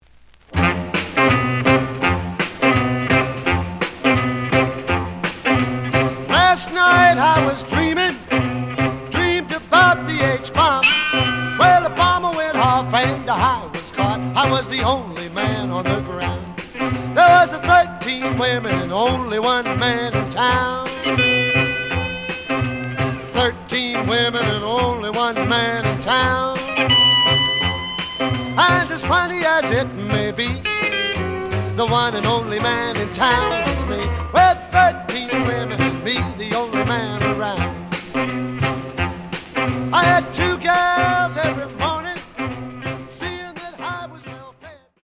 The audio sample is recorded from the actual item.
●Genre: Rhythm And Blues / Rock 'n' Roll
Slight edge warp. But doesn't affect playing. Plays good.)